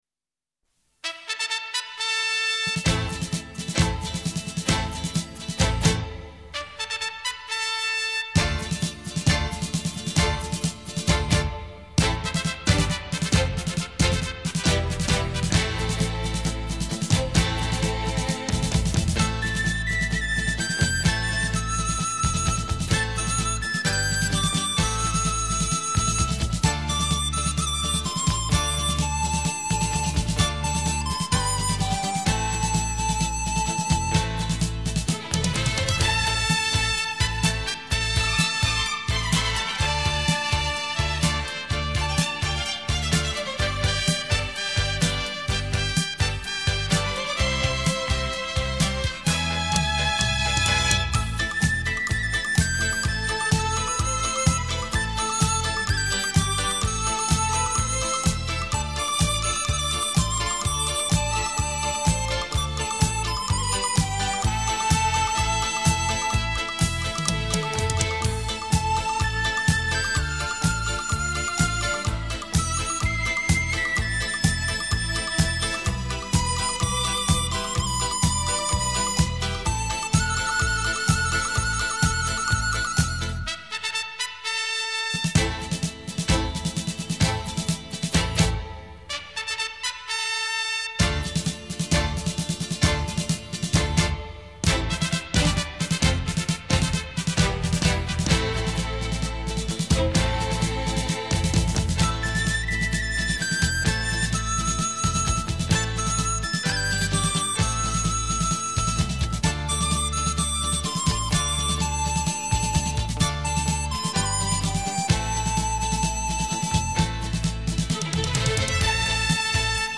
古箏金曲滿天星， 特殊演奏傳真情，
繞場立體音效美， 發燒音樂重炫韵。